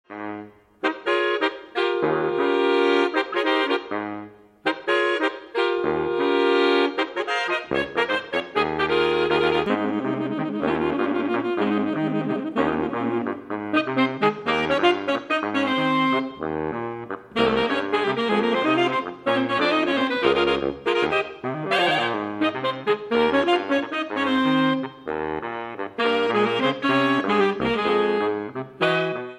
Jazz Saxophone Quartets are a passions of mine!
Hilo Haddy = Alto, Tenor 1, Tenor 2, Baritone (Fast Island Feel) The score showcases the melody.